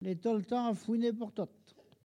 Localisation Bouin
Collectif atelier de patois
Catégorie Locution